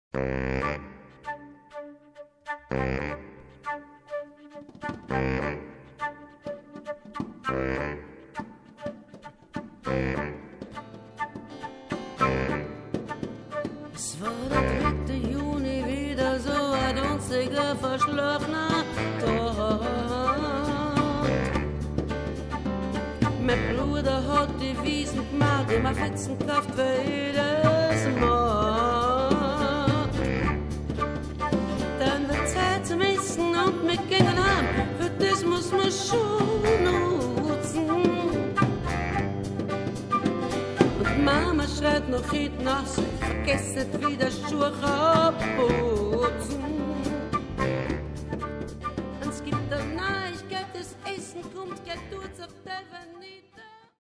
to the Viennese dialect